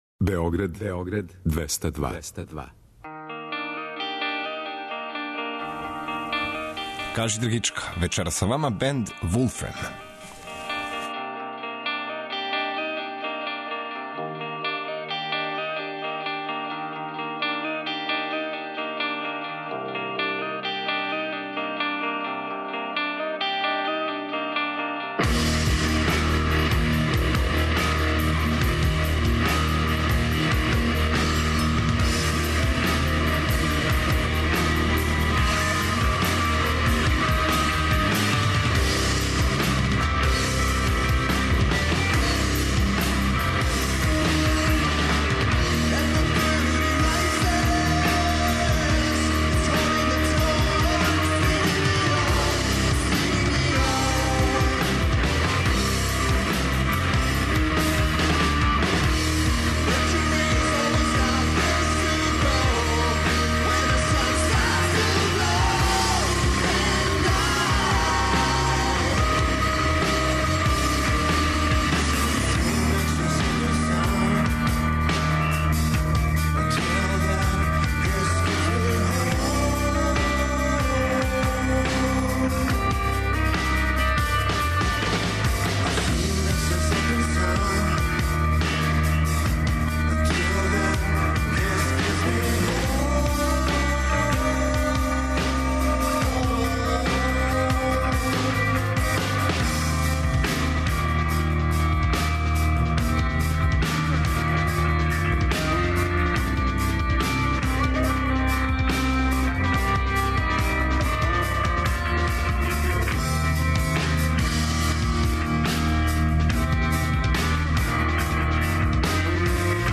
Свако вече, од поноћи на Двестадвојци у емисији Кажи драгичка гост изненађења!